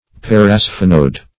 Search Result for " parasphenoid" : The Collaborative International Dictionary of English v.0.48: Parasphenoid \Par`a*sphe"noid\, a. [Pref. para- + sphenoid.]
parasphenoid.mp3